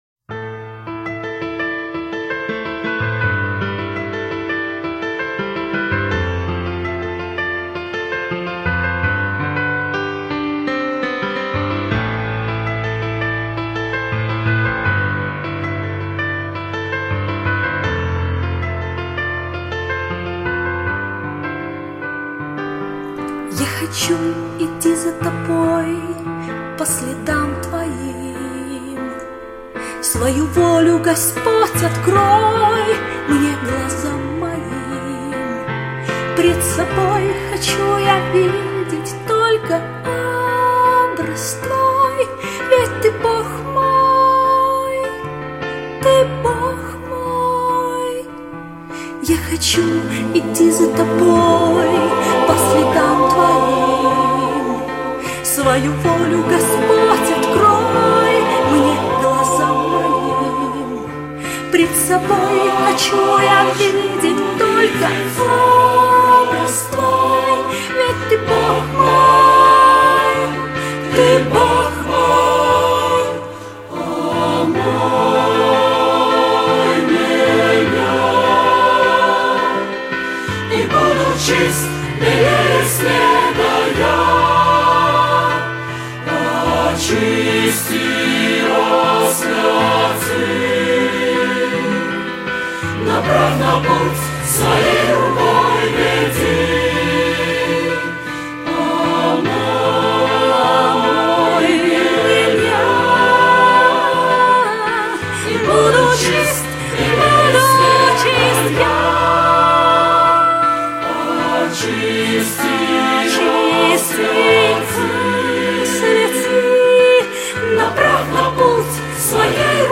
341 просмотр 328 прослушиваний 15 скачиваний BPM: 146